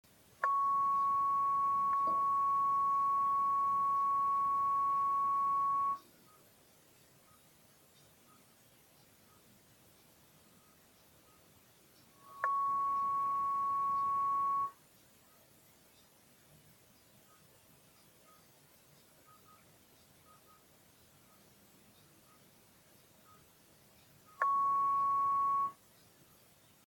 А теперь треки. Записывал сотиком, около пьезика.
Так что получилось очень живое, малопредсказуемое радио